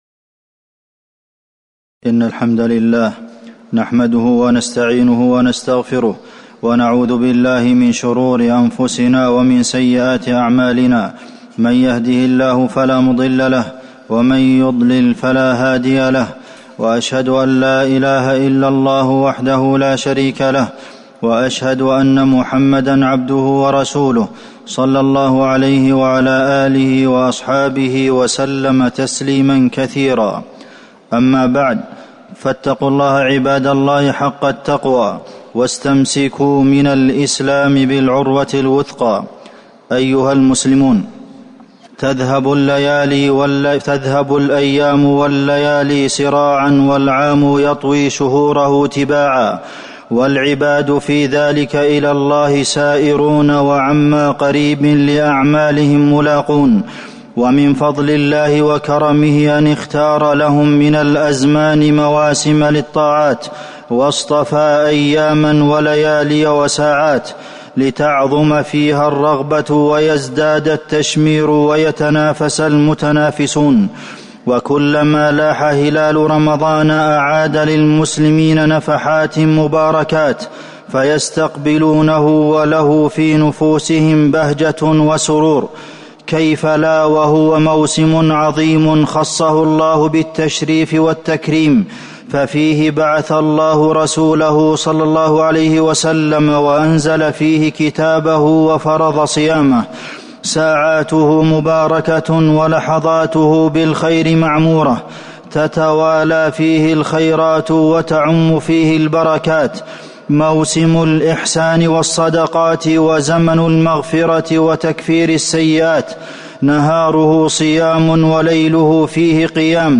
تاريخ النشر ٢٨ شعبان ١٤٤٠ هـ المكان: المسجد النبوي الشيخ: فضيلة الشيخ د. عبدالمحسن بن محمد القاسم فضيلة الشيخ د. عبدالمحسن بن محمد القاسم فضائل شهر رمضان